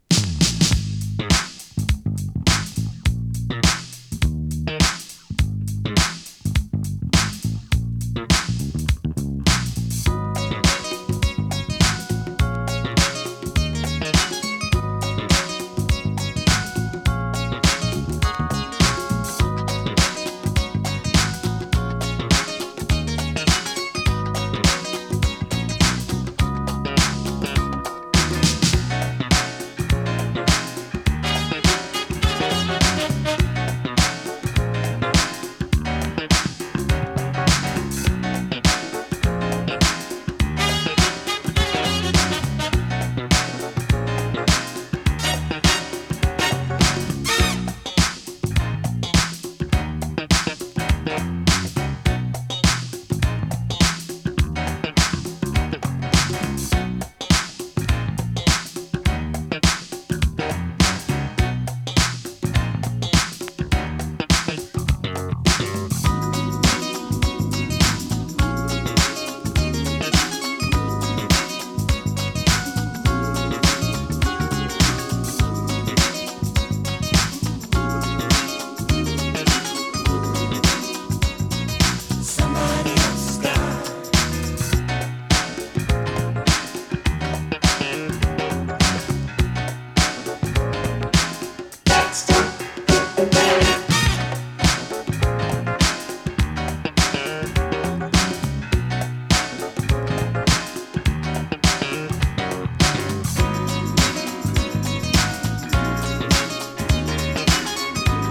ガラージュ ディスコ
B面にはインスト・ダブ・ヴァージョン(5.45)を収録しています。
♪Instr. Dub (5.45)♪